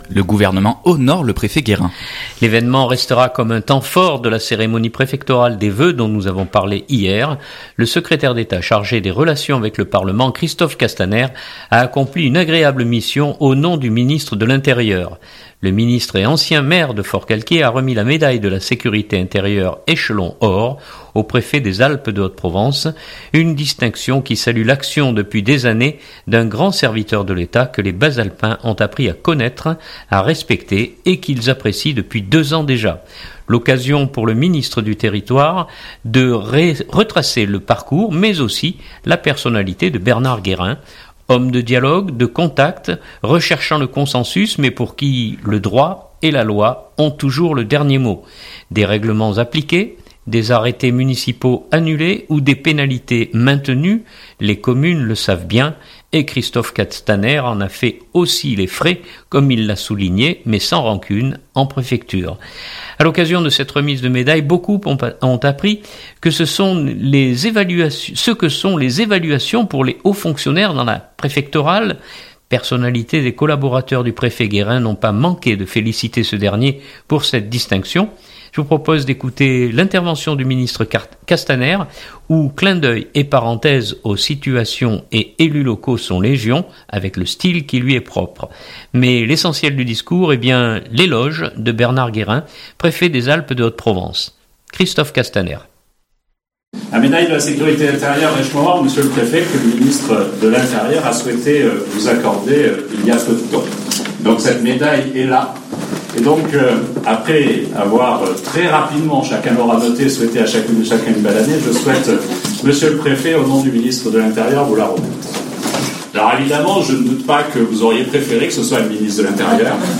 2018-01-16-Manosque-gouvernement et préfet.mp3 (7.67 Mo) L’événement restera comme un temps fort de la cérémonie préfectorale des vœux dont nous avons parlé hier.
Je vous propose d’écouter l’intervention du ministre Castaner où clins d’œil et parenthèses aux situations et élus locaux sont légion avec le style qui lui est propre. Mais l’essentiel du discours est bien l’éloge de Bernard Guérin, préfet des Alpes de Haute-Provence.